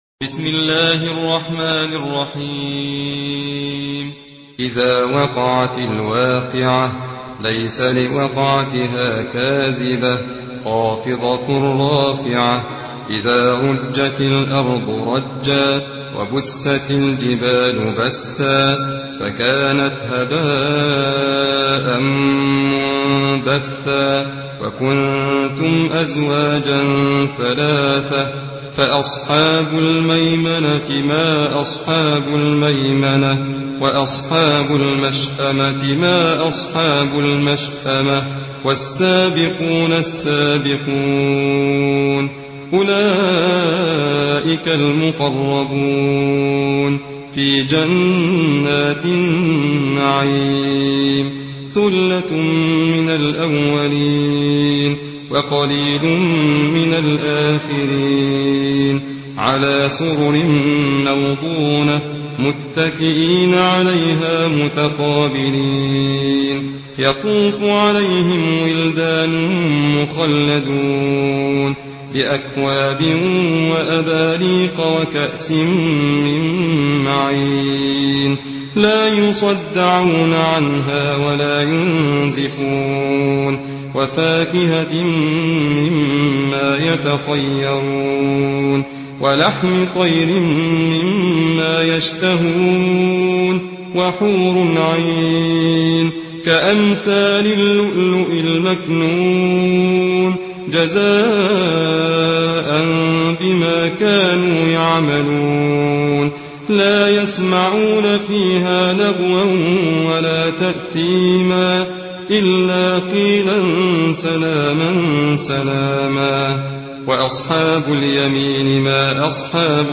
حفص عن عاصم